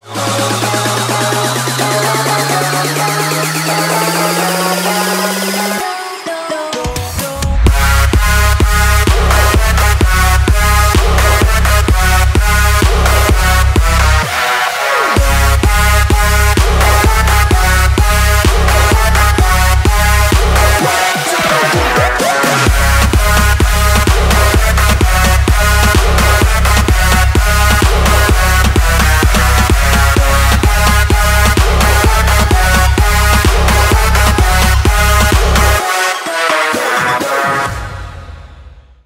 • Качество: 320, Stereo
громкие
мощные
Electronic
EDM
нарастающие
качающие
Big Room
Крутой дроп 8)